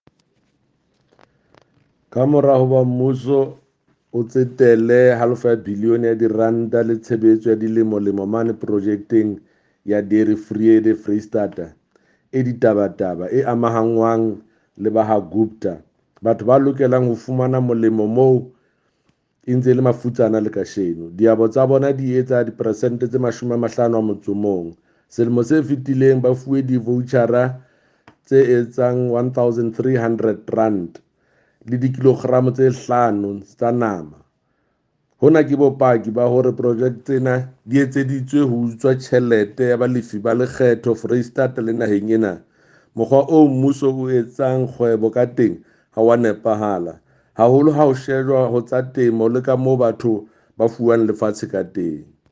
Sesotho soundbite by Jafta Mokoena MPL